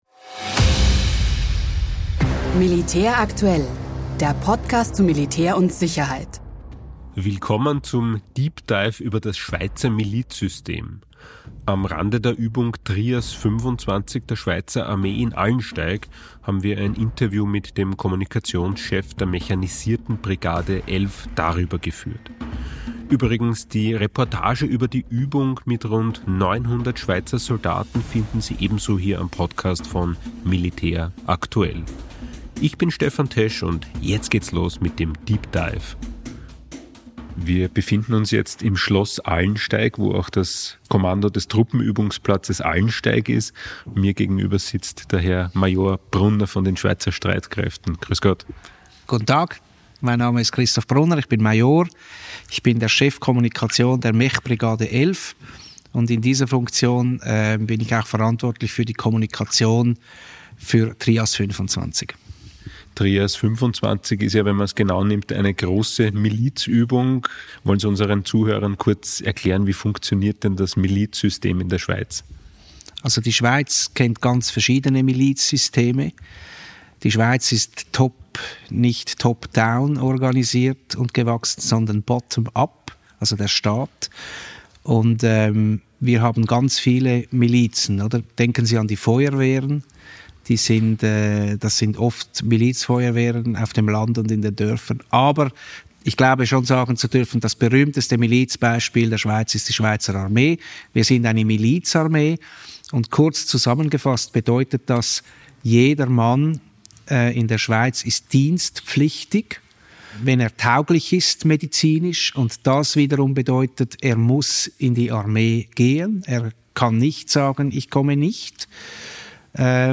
Interview über das Schweizer Milizsystem